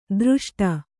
♪ dřṣṭa